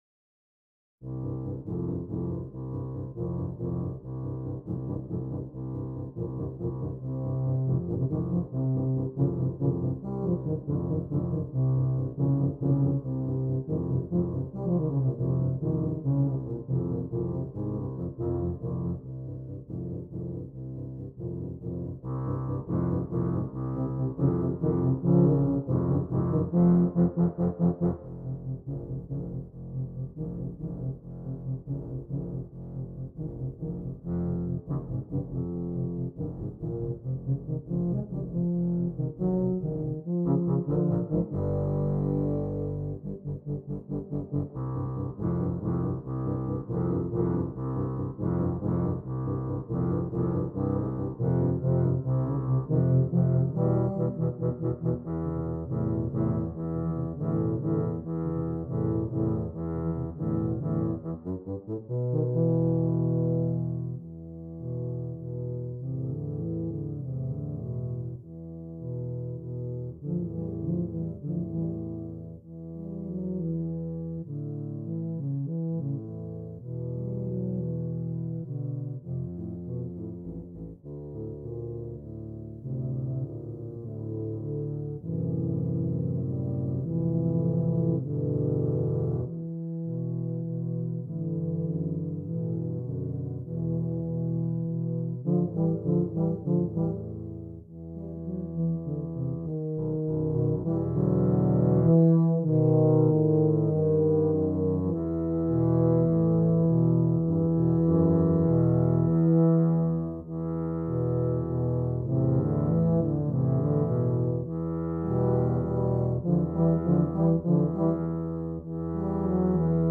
4 Tubas